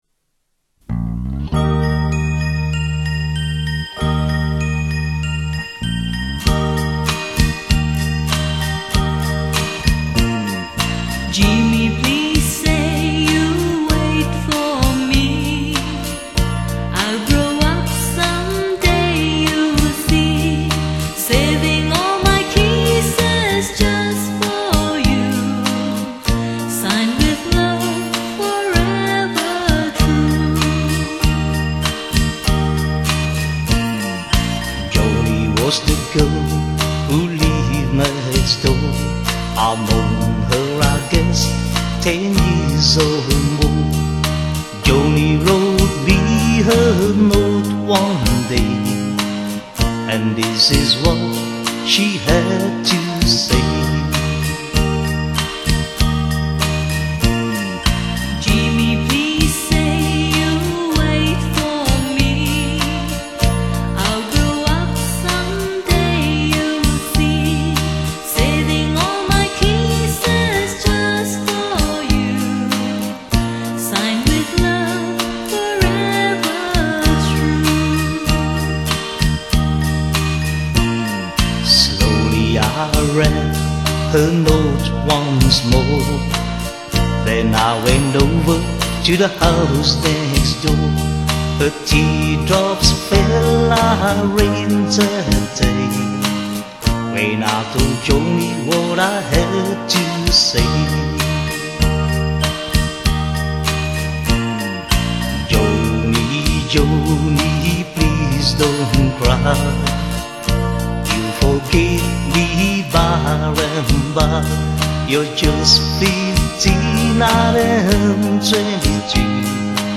语    种：纯音乐
木吉他的朴素和弦，民谣歌手的真情演绎，帮我们寻回人类的童真，生活的安宁。
[广告语] 乡村宁静雅致，民谣清新质朴。